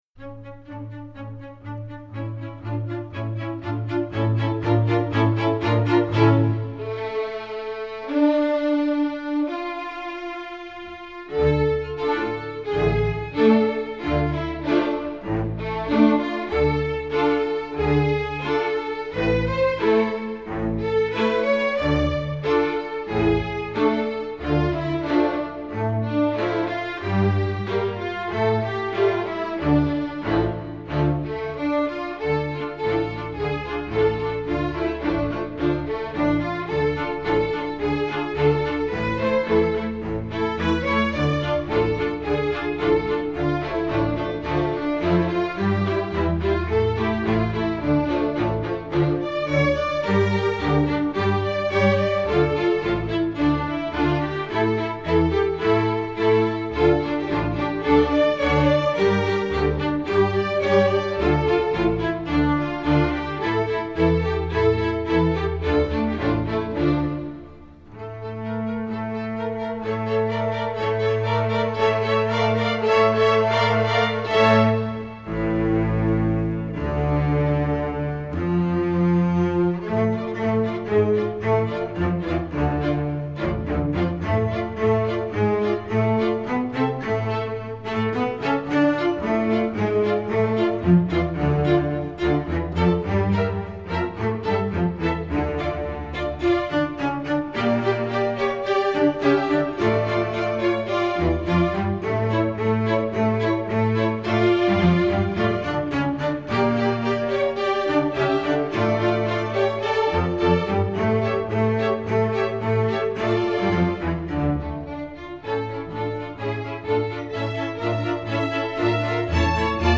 Composer: Traditional Hebrew
Voicing: String Orchestra